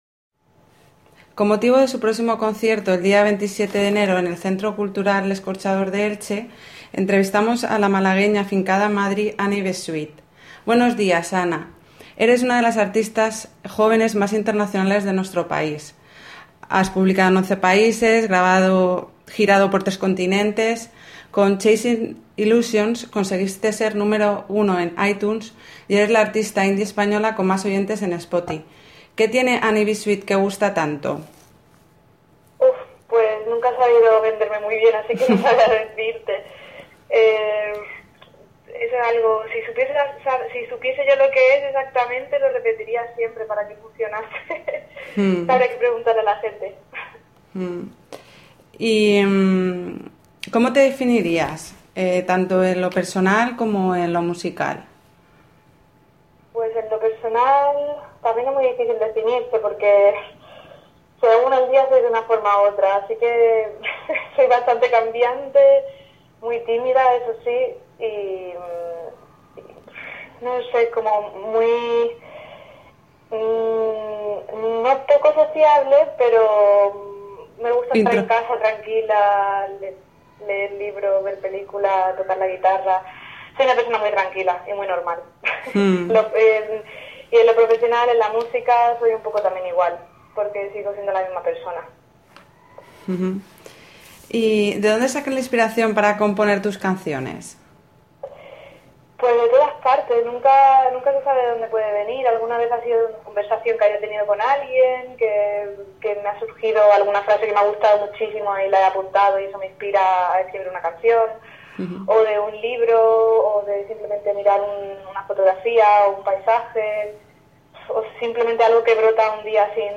Audio y texto de la entrevista